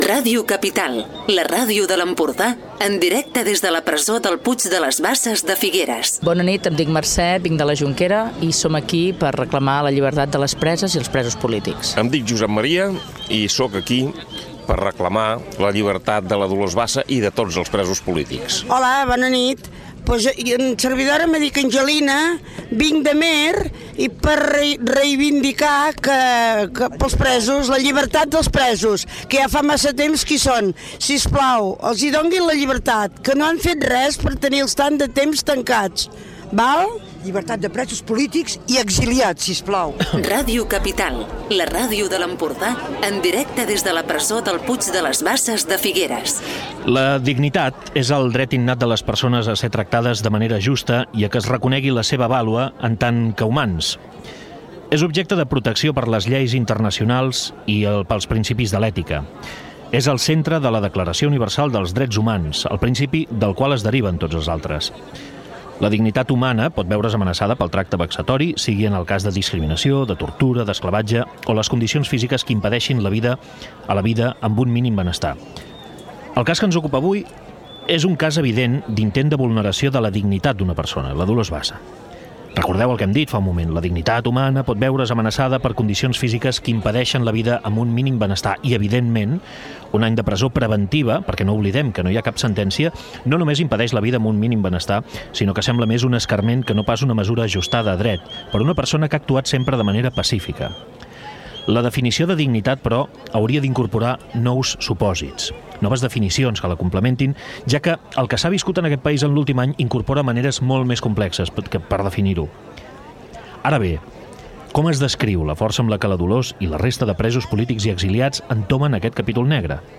30a5fc8bf5a782857a4154cd3d8ee34ff5fde74f.mp3 Títol Ràdio Capital Emissora Ràdio Capital Titularitat Tercer sector Tercer sector Altres Nom programa La ràdio a Puig de les Basses Descripció Programa especial fet des de l'aparcament exterior de la presó del Puig de les Basses per retre un homenatge a Dolors Bassa, l’exconsellera torroellenca tancada a la presó, en presó preventiva, i als centenars de persones que s’apleguen cada divendres pel sopar groc. Indicatiu del programa, declaracions de diverses persones, indicatiu del programa, comentari sobre la dignitat humana, estat del temps, entrevistes a persones que participen al sopar, persones que intervindran al programa, com s'ha arribat a aquesta situació, equip del programa, perfil biogràfic de Dolors Bassa